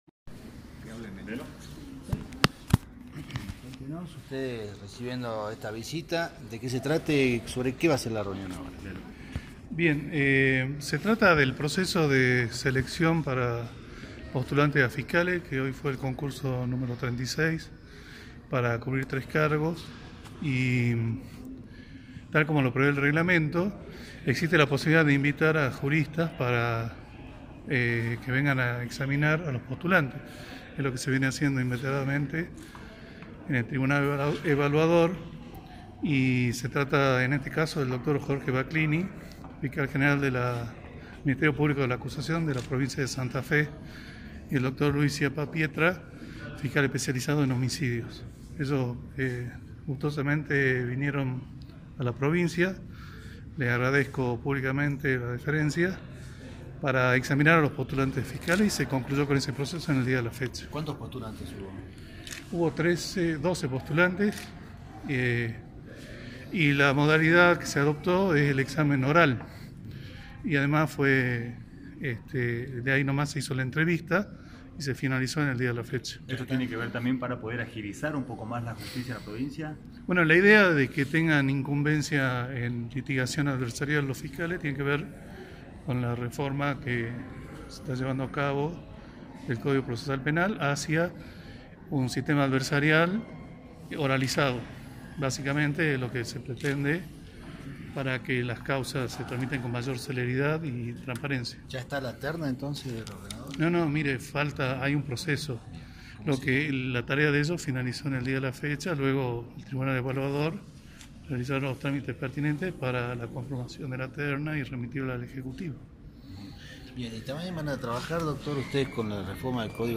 El Fiscal Dr Lello Sanchez y el Fiscal General de la provincia de Santa Fe Jorge Camilo Baclini se refirieron a la participación en el tribunal evaluador para la seleccion de fiscales.